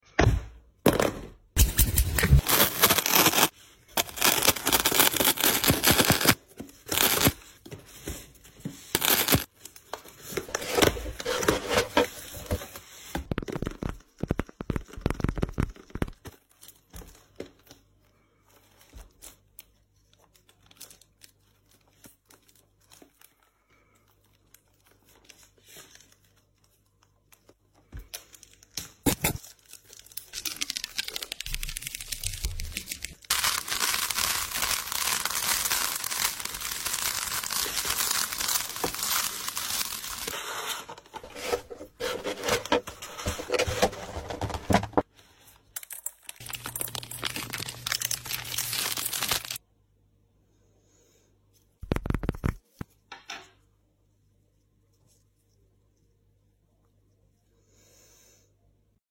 Star Wars wikkeez Asmr sound effects free download